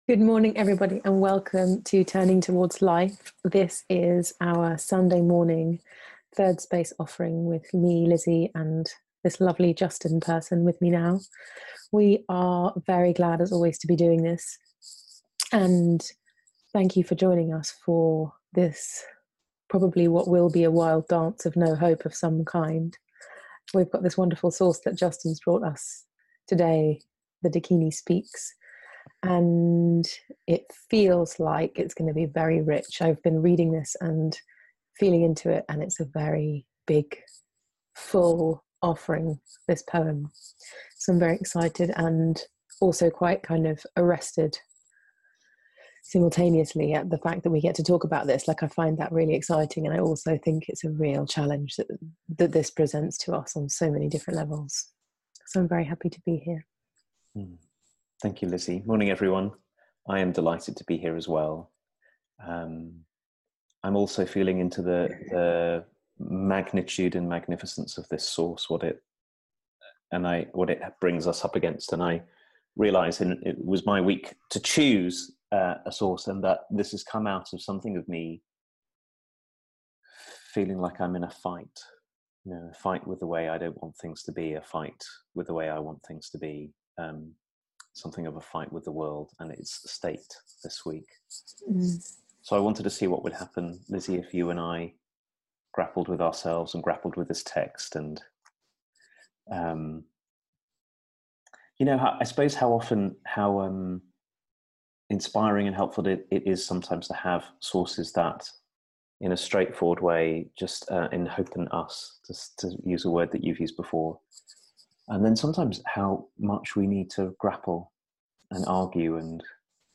A conversation about life's impermanence